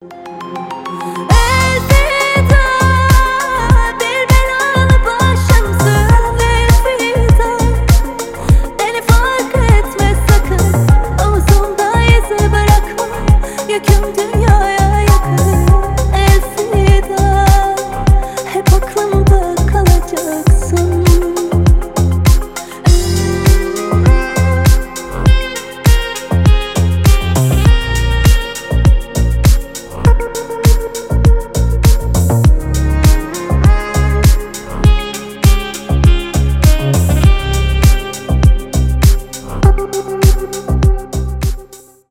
deep house , нежные , восточные
ремиксы
танцевальные
красивый женский голос